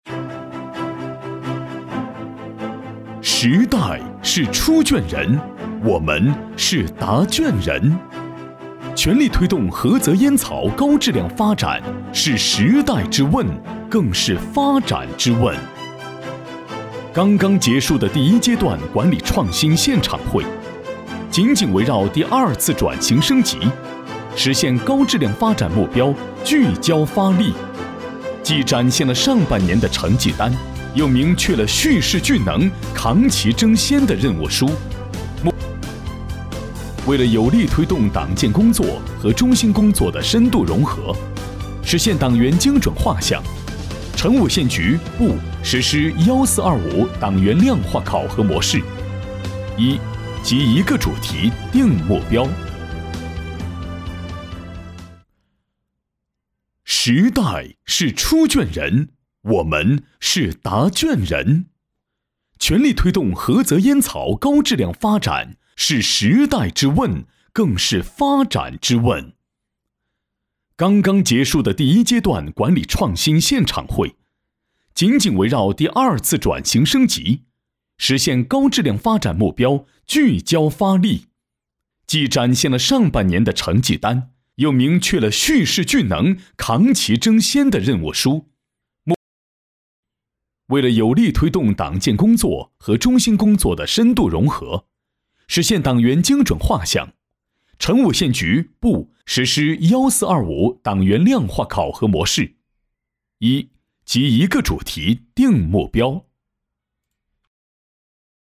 204男-大气激情-宣传片
擅长：专题片 广告
特点：大气浑厚 稳重磁性 激情力度 成熟厚重
风格:浑厚配音